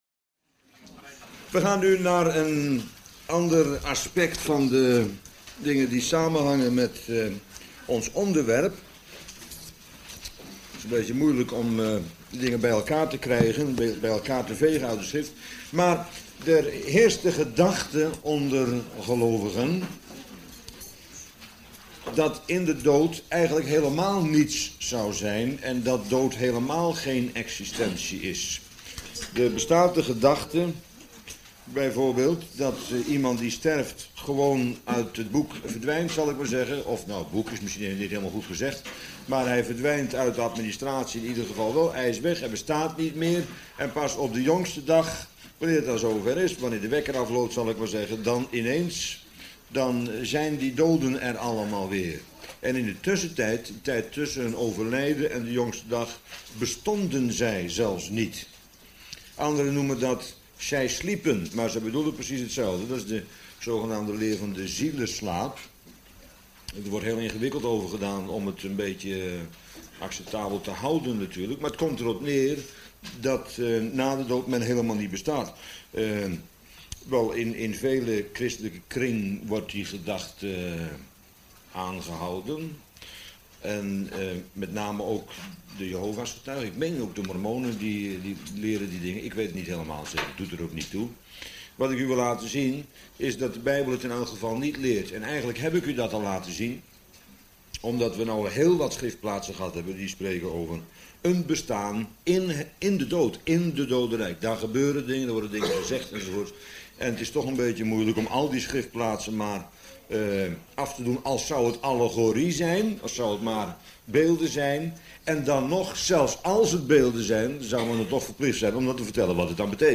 Bijbelstudie lezingen